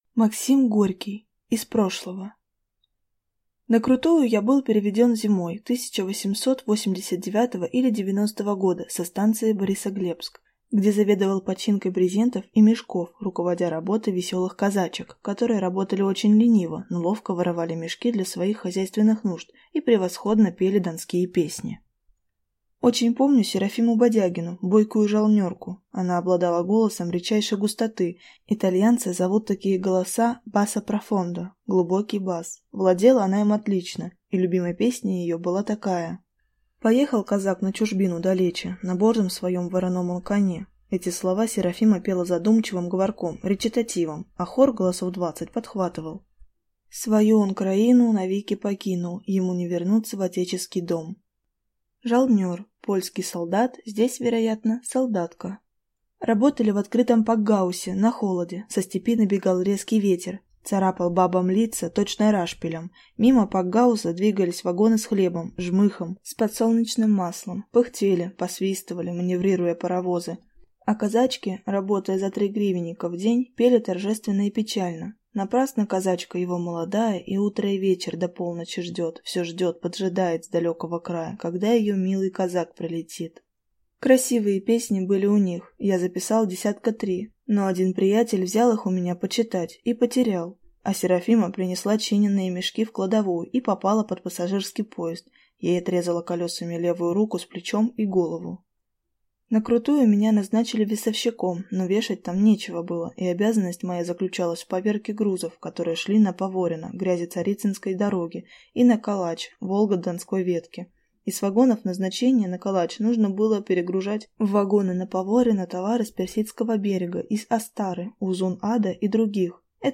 Аудиокнига Из прошлого | Библиотека аудиокниг